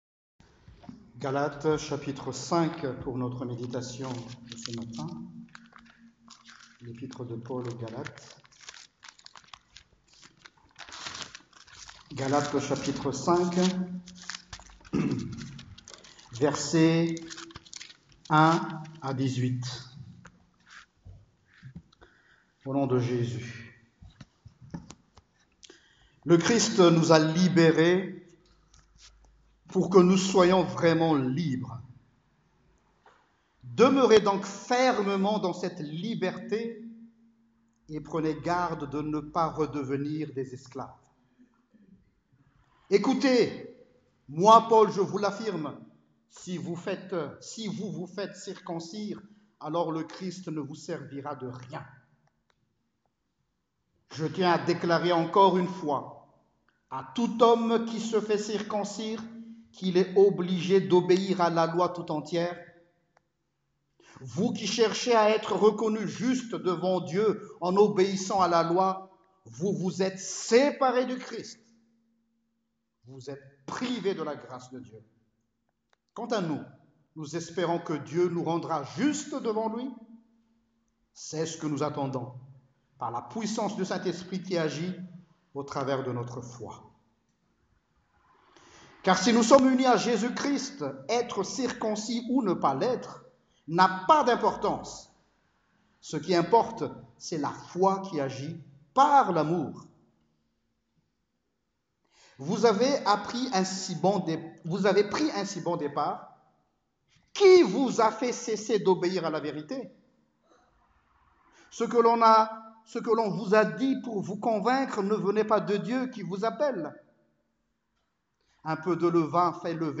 Prédication du 30 Juin 2019